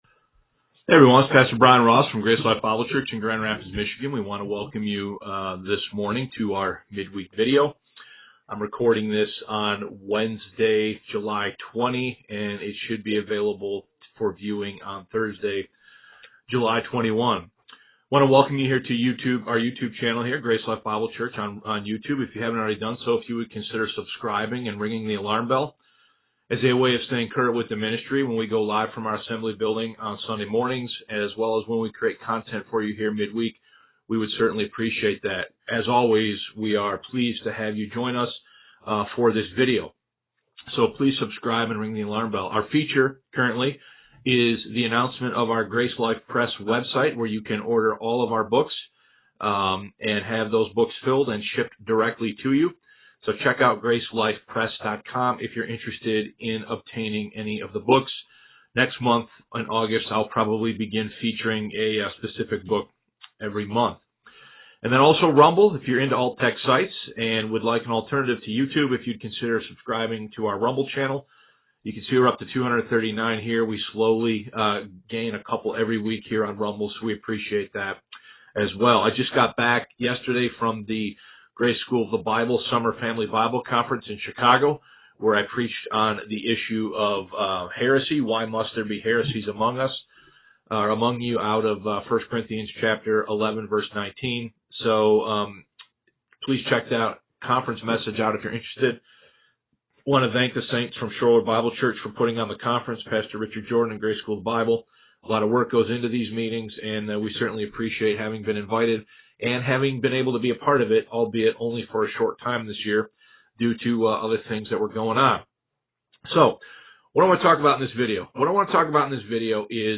Facebook Live Videos (Vlogs) , Mid-Week Messages